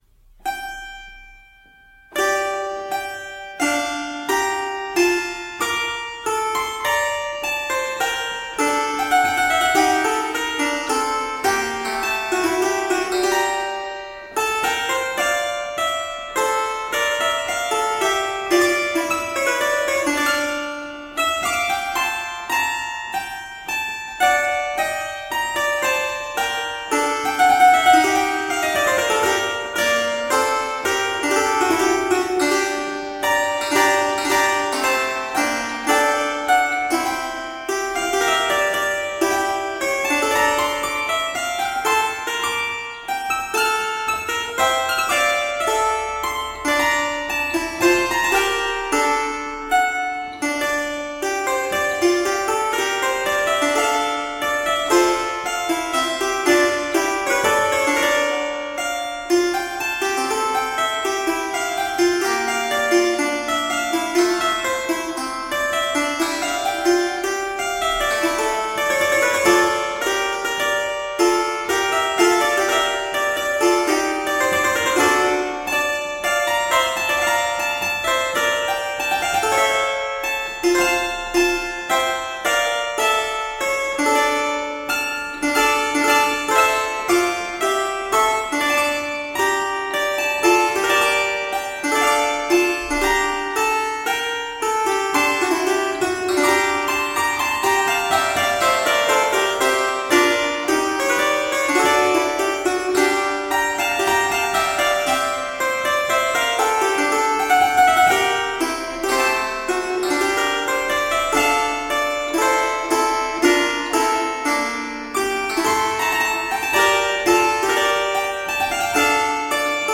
Solo harpsichord music
Classical, Baroque, Instrumental Classical
Harpsichord